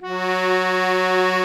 F#3 ACCORD-R.wav